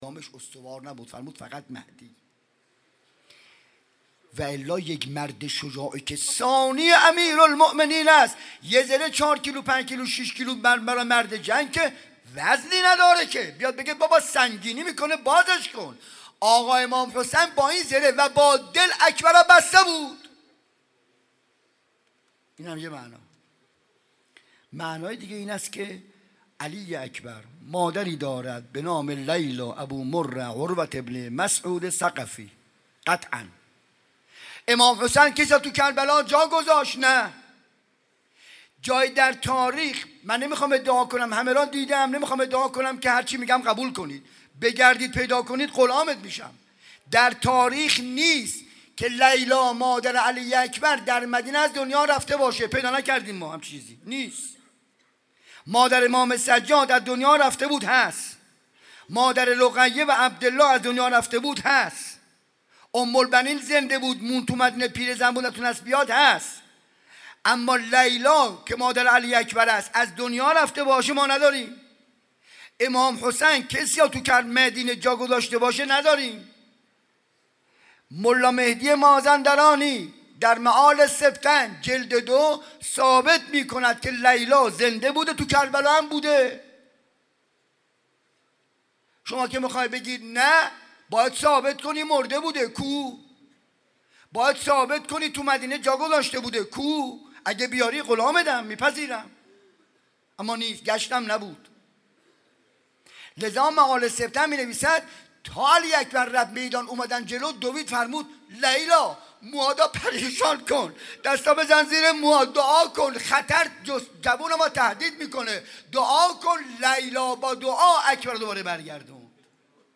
شب هشتم محرم 95_سخنراني_بخش ششم